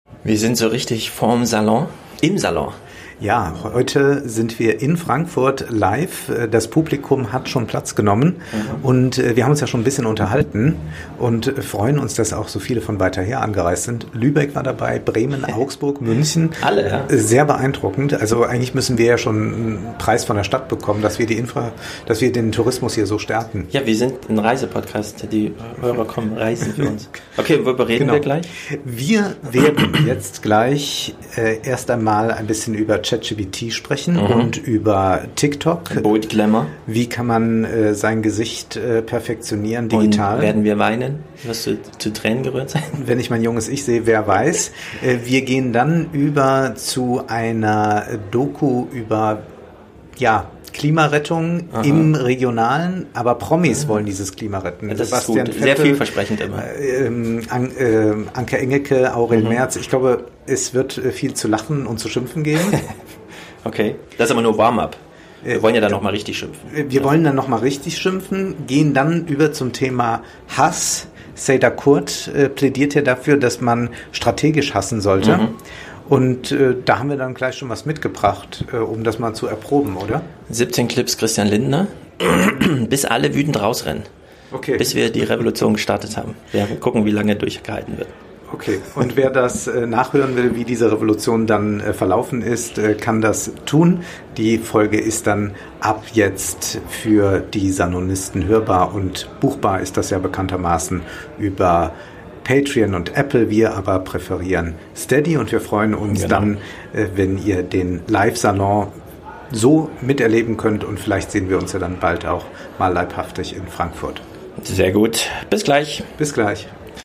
Live in Frankfurt, Salon Teaser